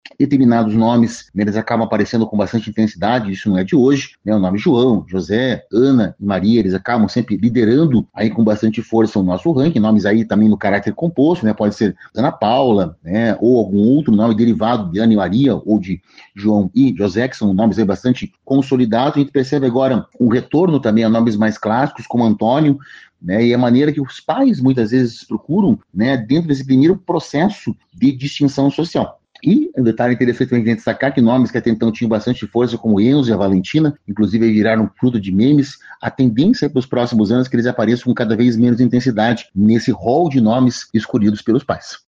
O especialista ainda destaca o retorno de nomes mais clássicos aos registros recentes e comenta uma das tendências para o futuro.